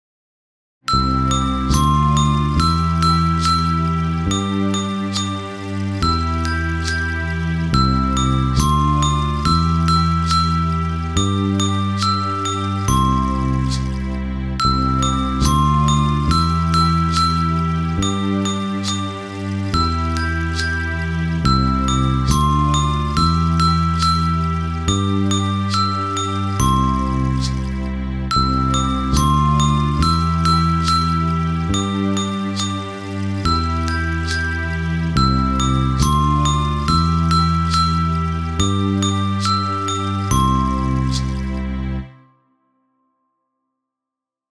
Chime Bars Ensemble